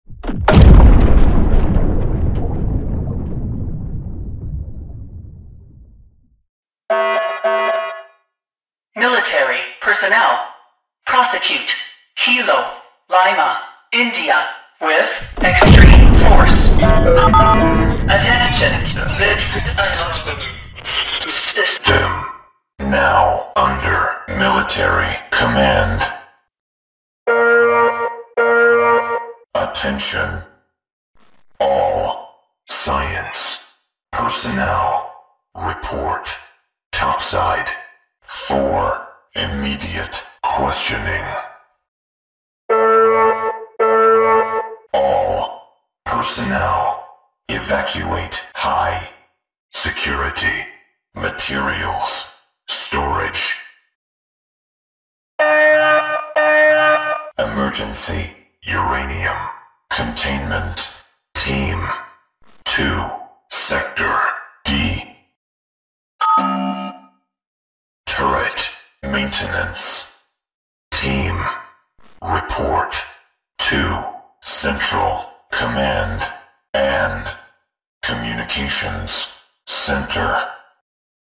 military-command.mp3